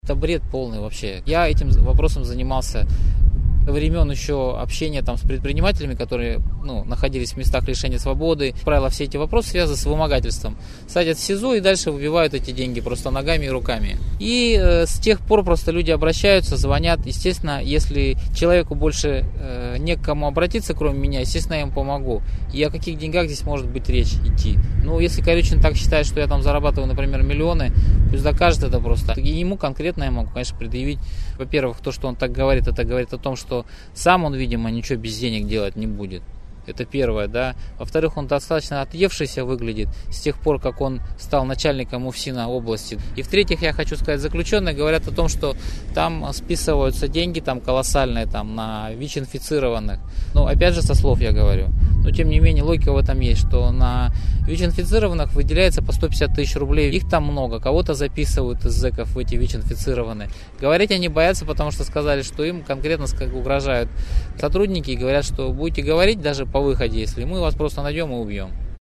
Правозащитник